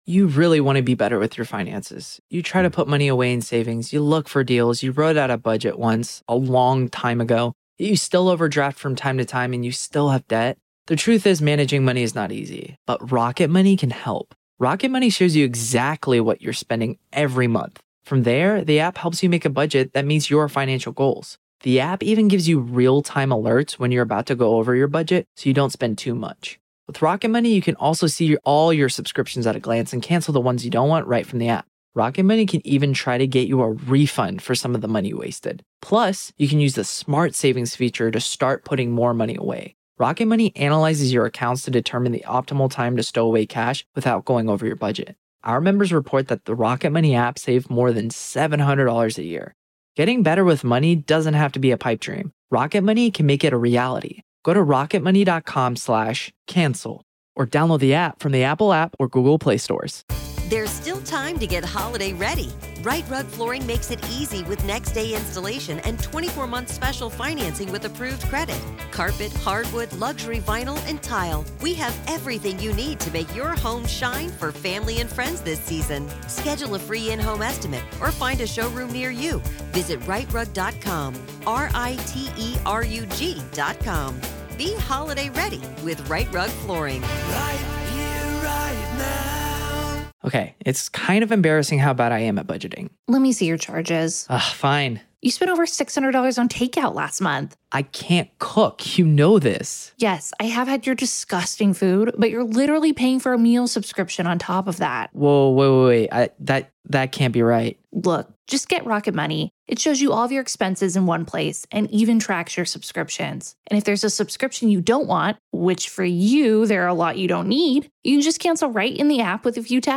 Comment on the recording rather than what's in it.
You’ll hear unfiltered courtroom audio, direct from the trial